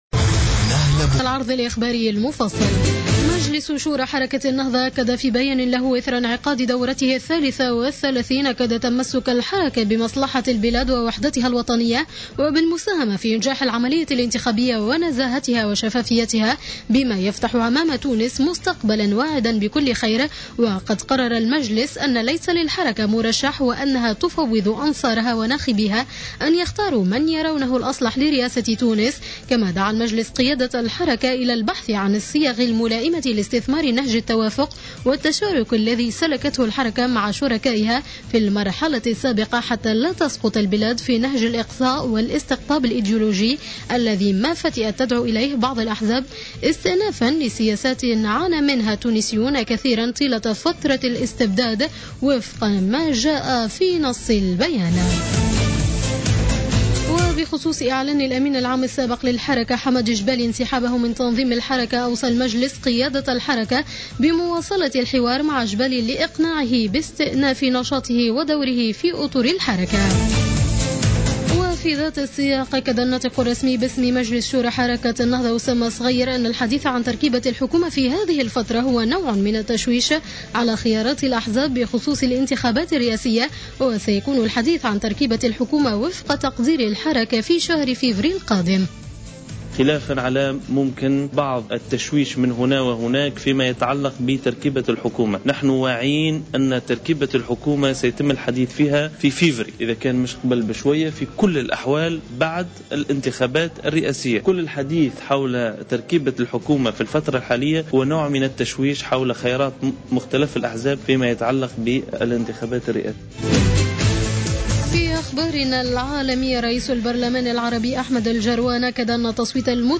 نشرة أخبار منتصف الليل ليوم الأحد 14-12-14